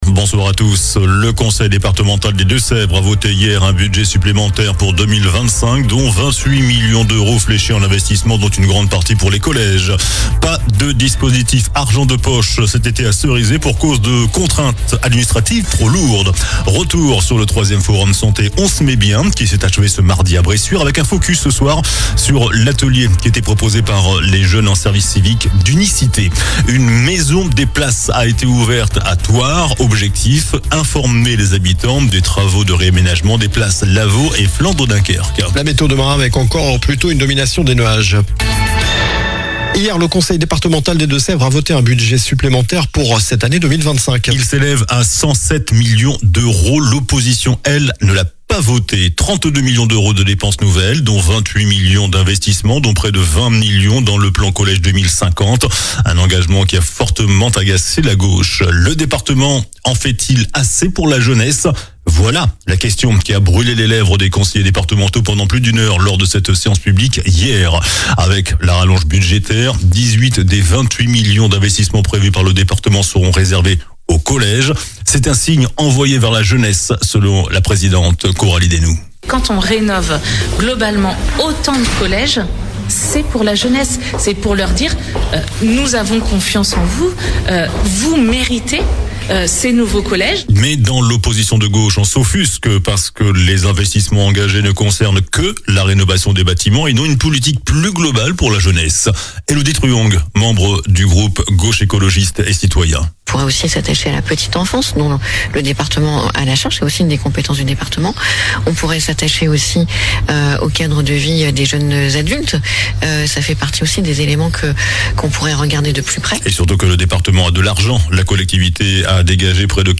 JOURNAL DU MARDI 27 MAI ( SOIR )